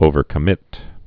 (ōvər-kə-mĭt)